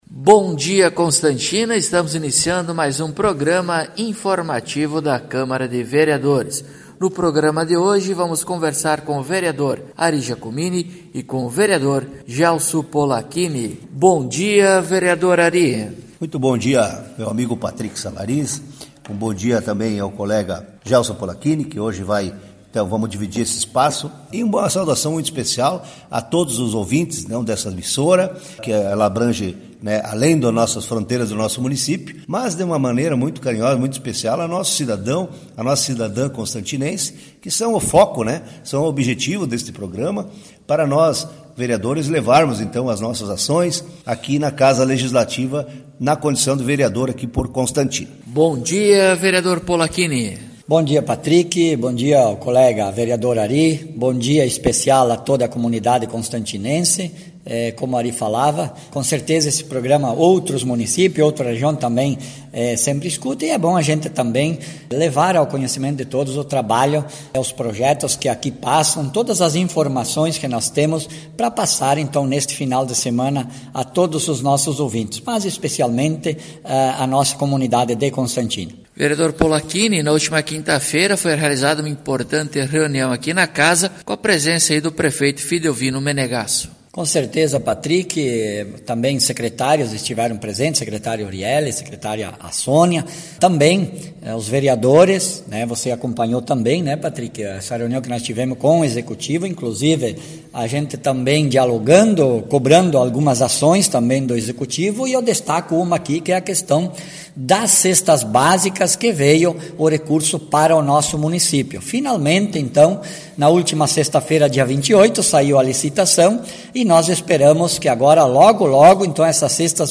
Acompanhe o programa informativo da câmara de vereadores de Constantina com o Vereador Ari Giacomini e o Vereador Gelso Polaquini.